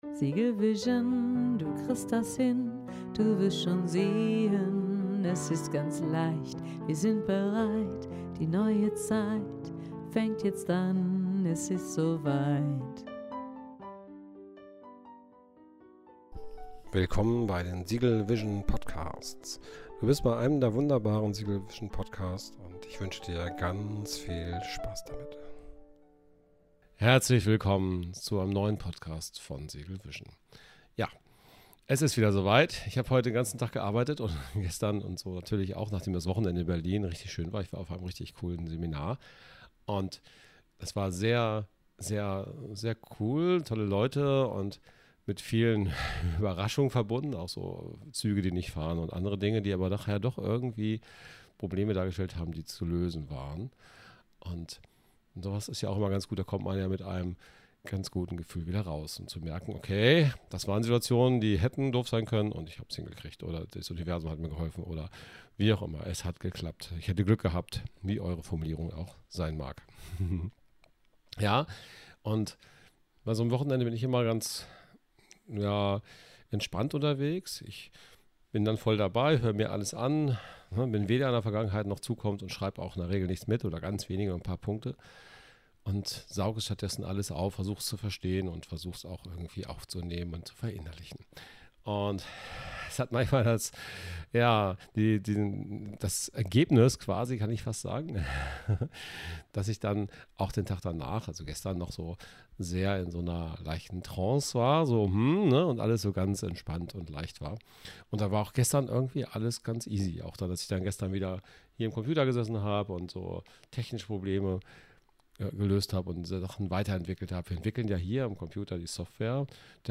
Seminar in Berlin Hindernisse bei der Softwareentwicklung der Nutzen von Fehlschlägen Es geht immer irgendwie